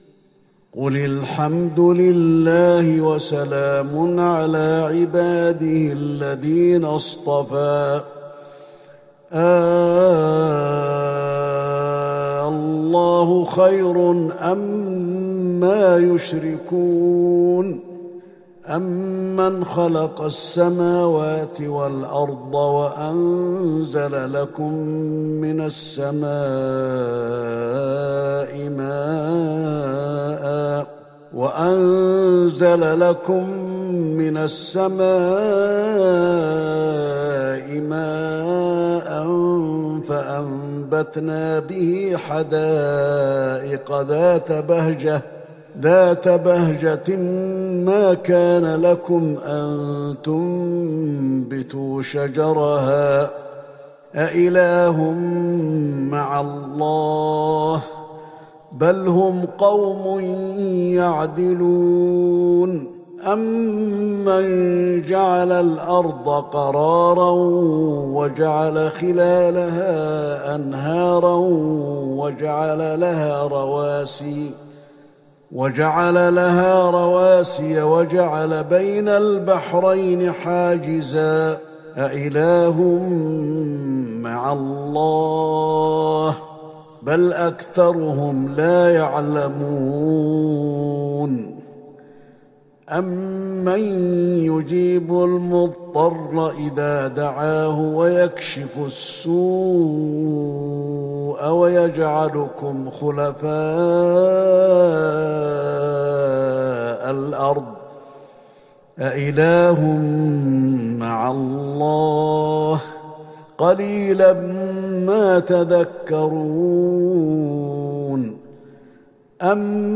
تهجد ٢٣ رمضان ١٤٤١هـ من سورة النمل { ٥٩- النهاية { > تراويح الحرم النبوي عام 1441 🕌 > التراويح - تلاوات الحرمين